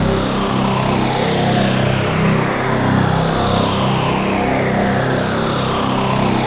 AMB REZ WA.wav